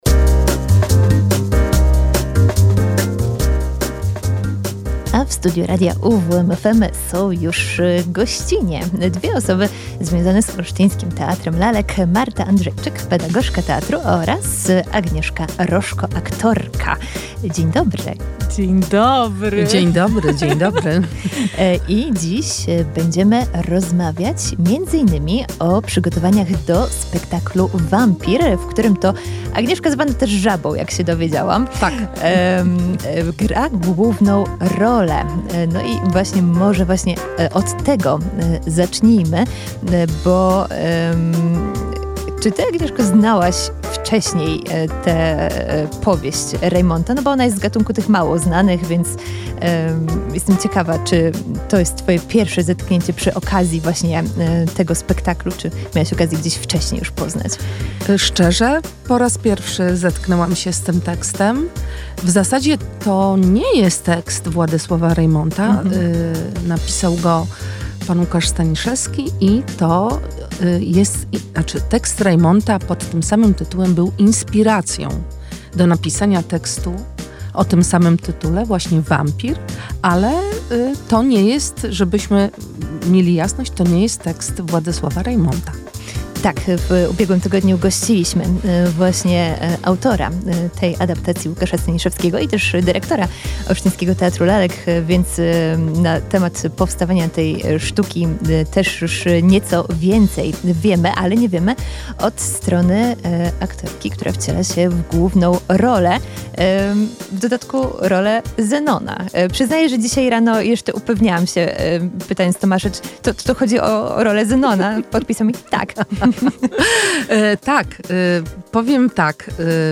W Olsztyńskim Teatrze Lalek trwają intensywne przygotowania do premiery spektaklu „Wampir”, który już w listopadzie otworzy nową scenę teatru. O pracy nad przedstawieniem, kulisach prób i aktorskich wyzwaniach w studiu Radia UWM FM opowiadały